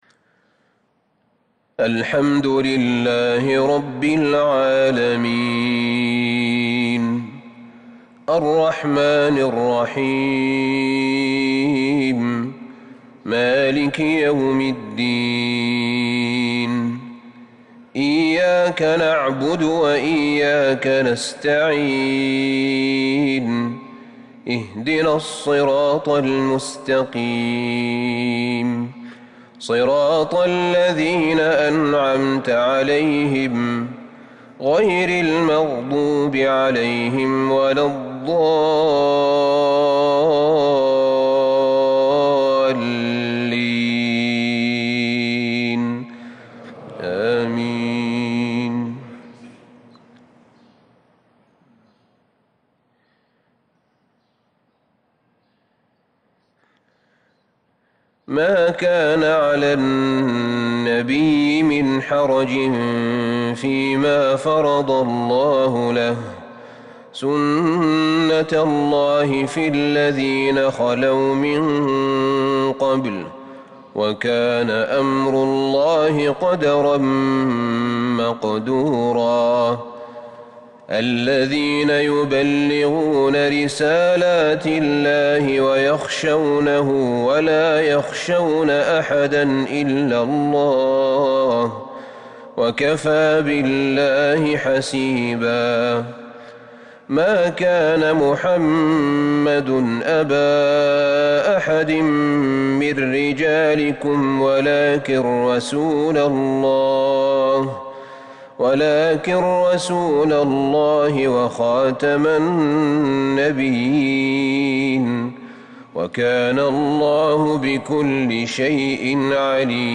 عشاء السبت 3-6-1442هـ من سورة الأحزاب | Isha Prayar from Surah AlAhzab 16/1/2021 > 1442 🕌 > الفروض - تلاوات الحرمين